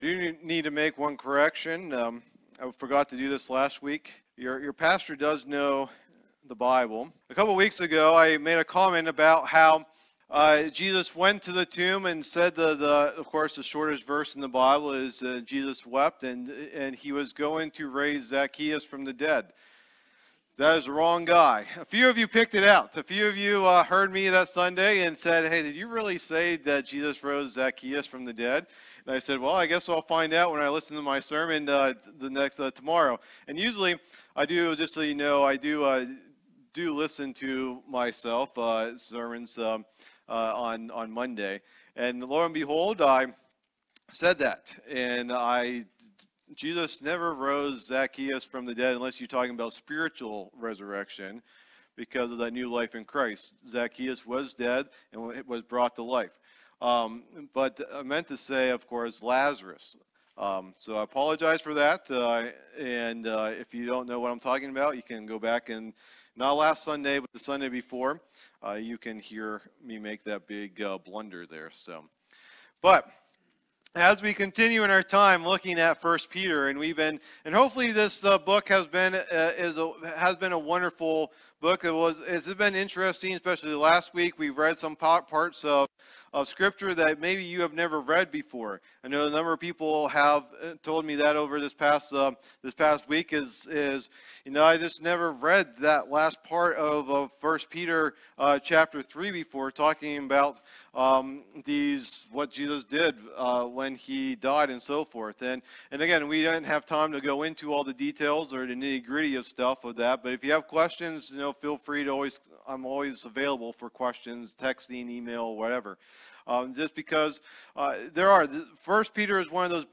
Message #12 in the "1 Peter" teaching series